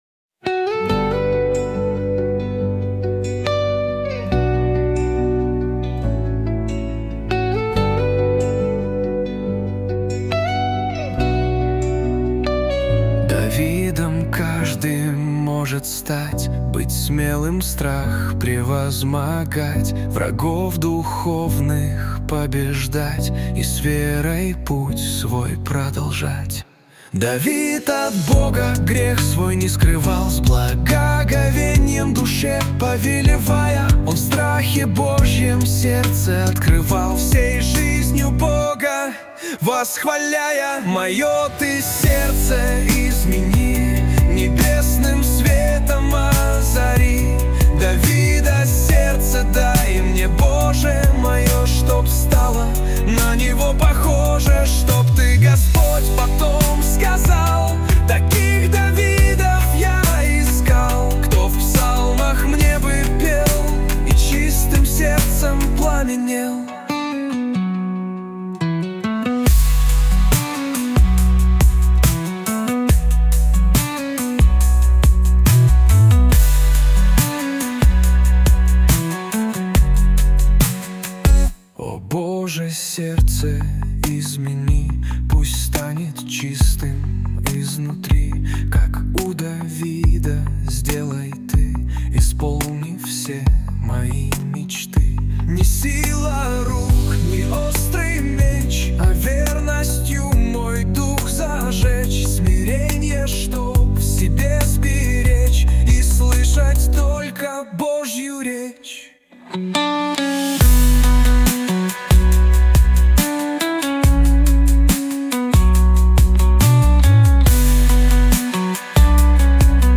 песня ai
306 просмотров 1119 прослушиваний 88 скачиваний BPM: 70